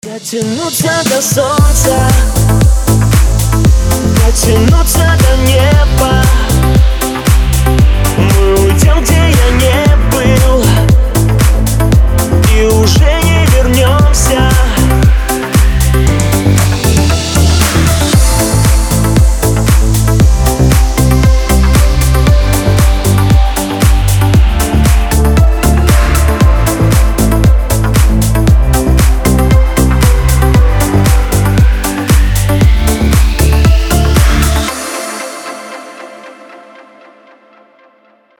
• Качество: 320, Stereo
поп
deep house